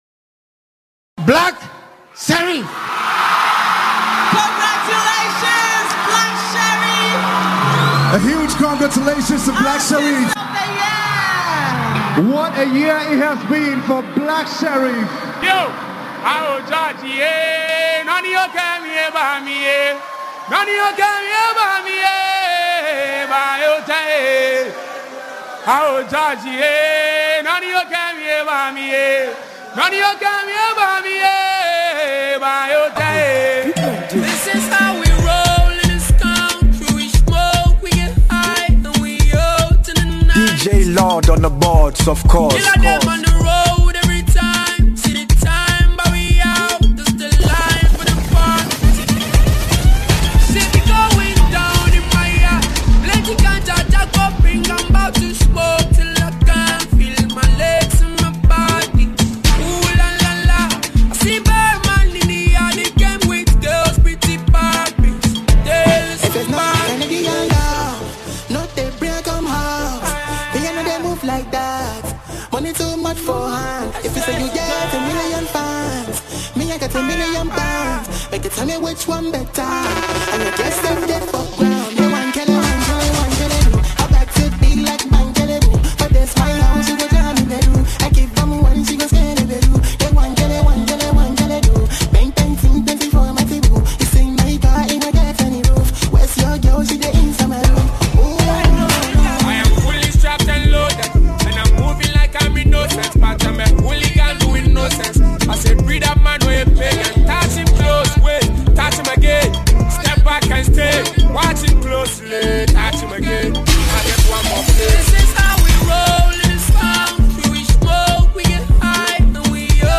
a trending Ghanaian disc jockey who holds countless awards